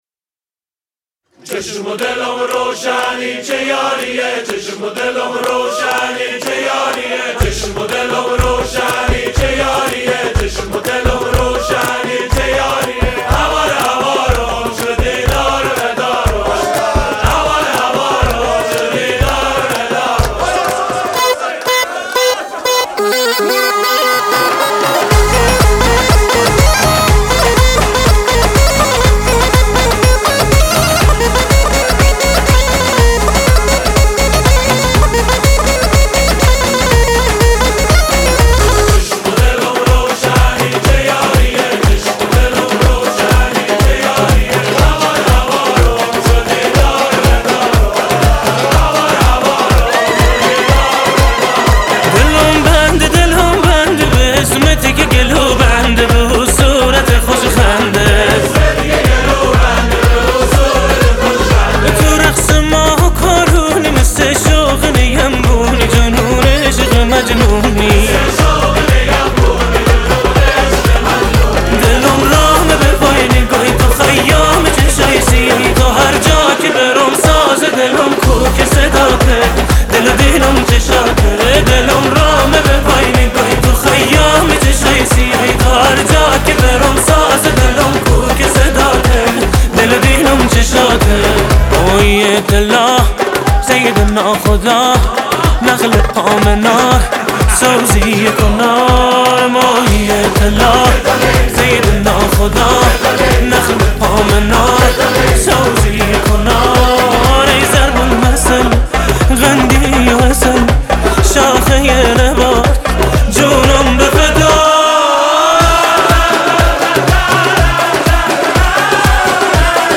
۱۷ خرداد ۱۴۰۳ موزیک ایرانی